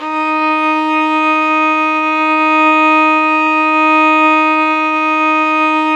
Index of /90_sSampleCDs/Roland - String Master Series/STR_Violin 4 nv/STR_Vln4 _ marc